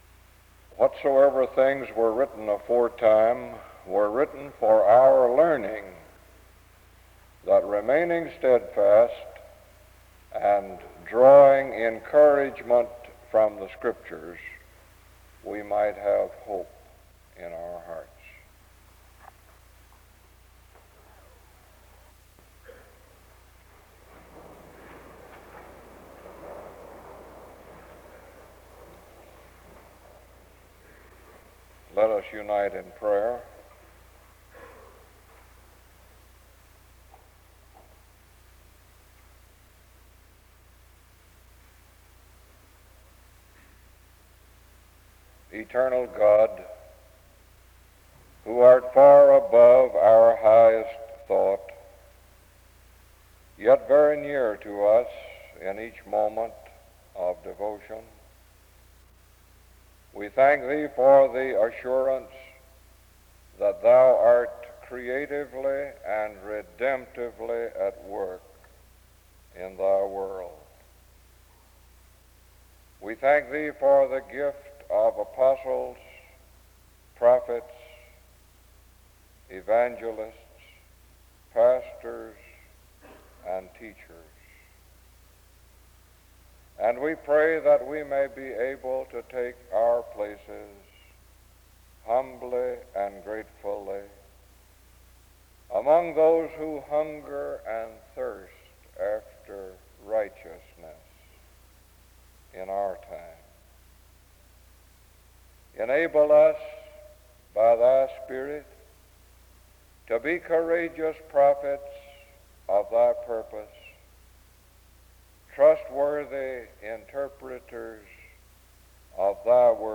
D.C. The service begins with a scripture reading and prayer from 0:00-3:04.
An introduction to the speaker is given from 3:09-4:46.